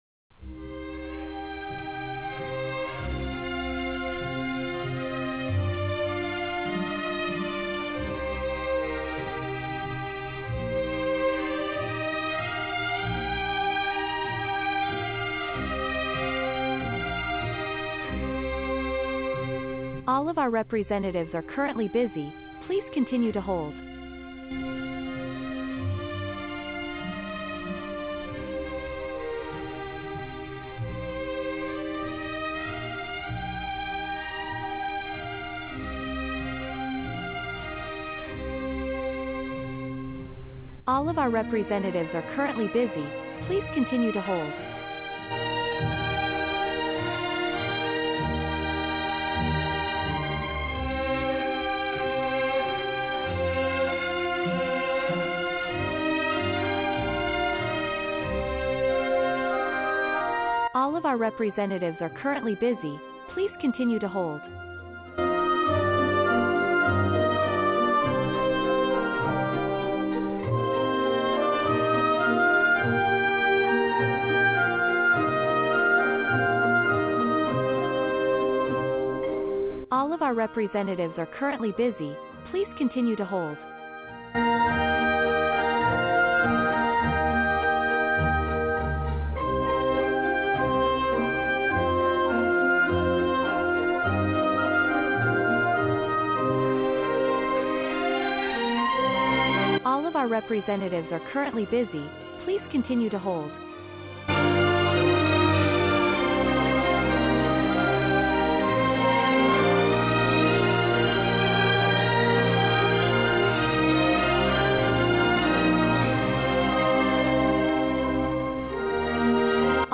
Professional audio files for your business phone system
Enhanced Queue Message